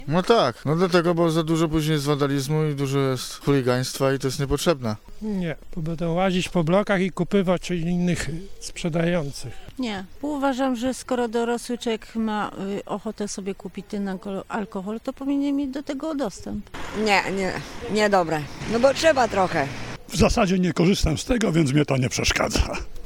Zapytaliśmy mieszkańców Stargardu, co sądzą o takim rozwiązaniu.
„Tak, bo później jest za dużo wandalizmu i chuligaństwa” – mówi jeden z mieszkańców.
„Skoro dorosły człowiek ma ochotę kupić alkohol, to powinien mieć do tego dostęp” – uważa jedna z mieszkanek.